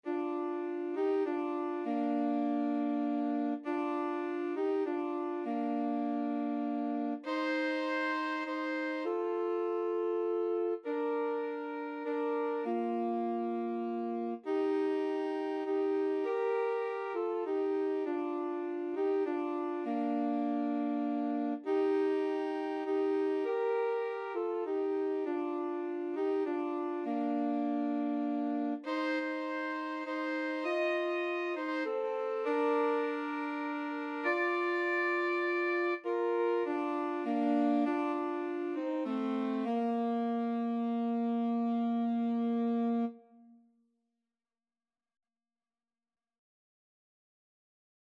Alto SaxophoneTenor Saxophone
6/8 (View more 6/8 Music)